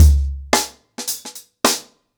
HarlemBrother-110BPM.27.wav